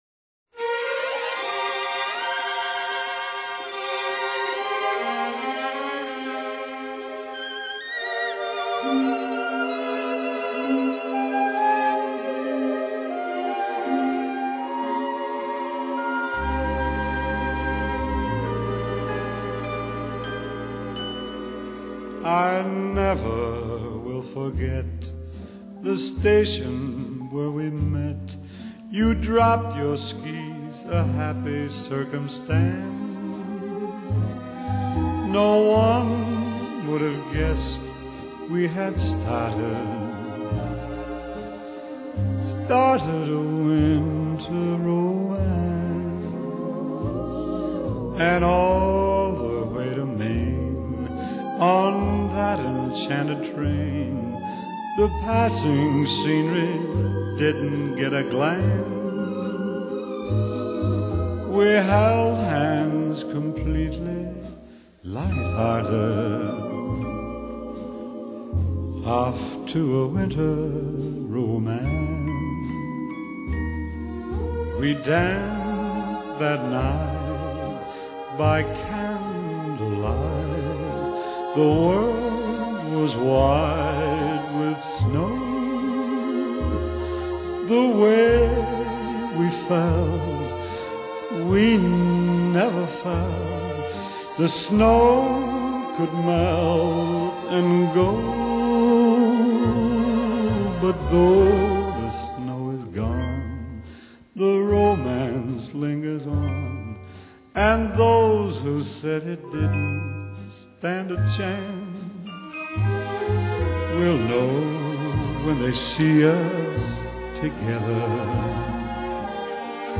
seasonal